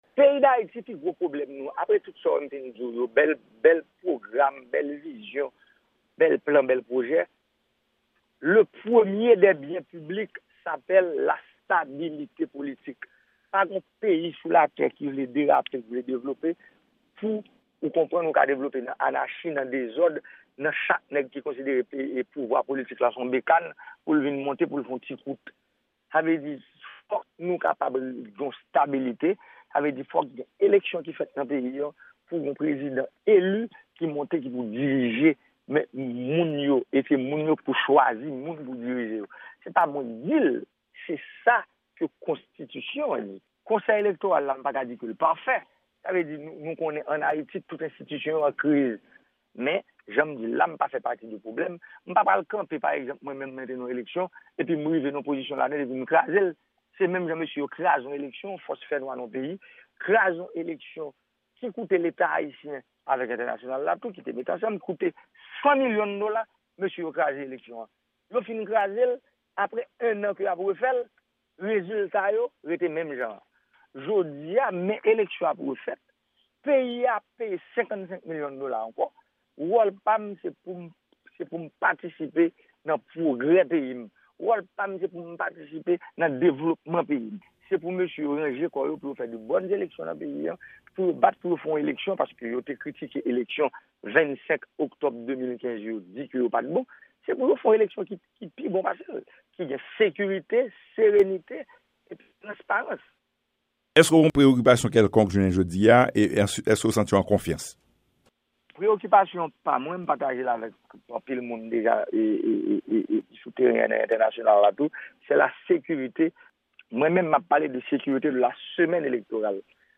Entèvyou
depi Washington, DC